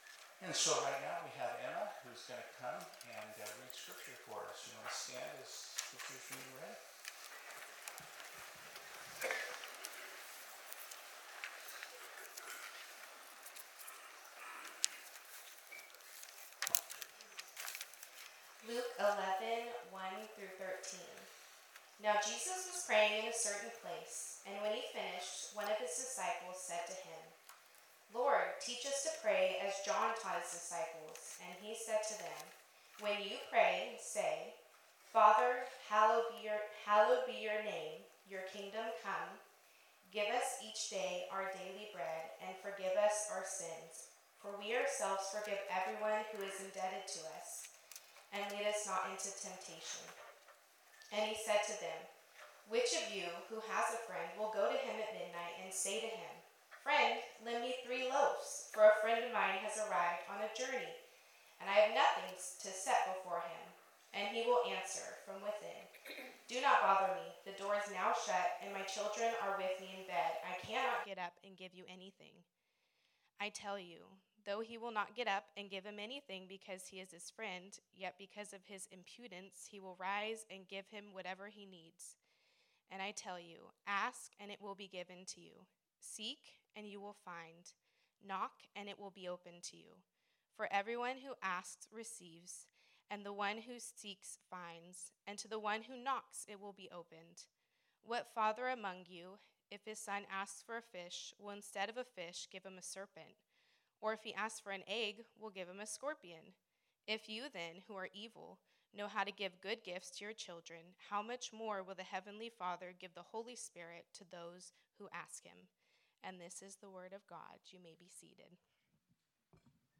Series: The Gospel of Luke Type: Sermon